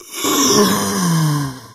burer_idle_0.ogg